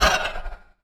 etfx_shoot_soul.wav